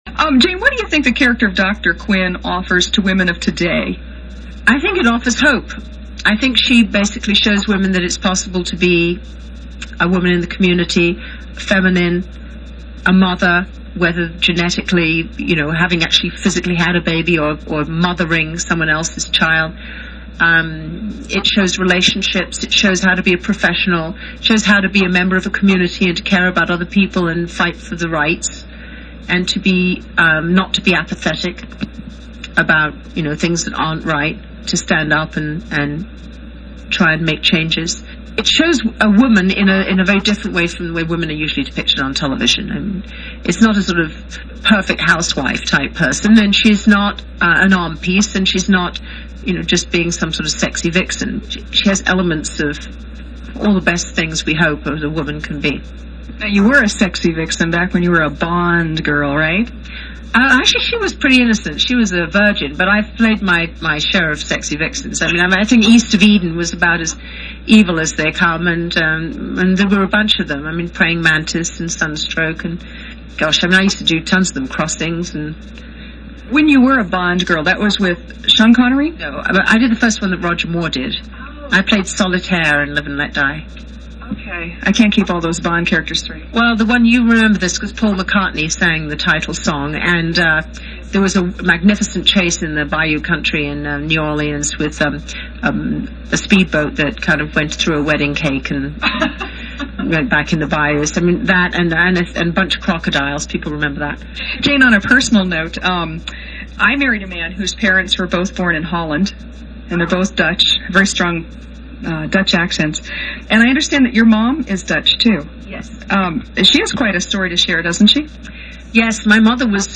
Jane Seymour on the set of Dr. Quinn